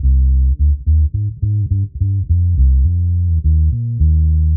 Index of /musicradar/dub-designer-samples/105bpm/Bass
DD_PBass_105_C.wav